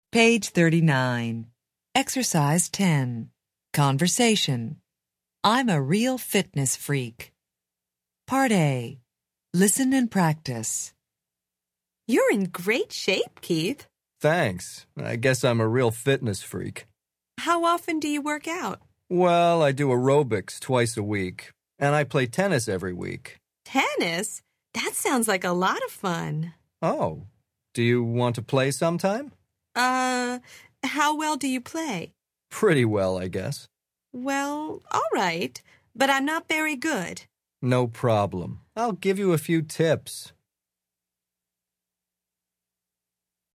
Interchange Third Edition Level 1 Unit 6 Ex 10 Conversation Track 18 Students Book Student Arcade Self Study Audio